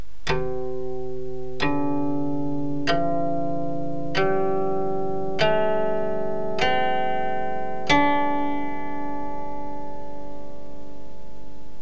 산음
• 산 - 왼손가락을 사용하지 않음 (개방현)
• 산음(散音): 오른손 손가락으로 현을 뜯어 만드는 기본 주파수의 소리이다.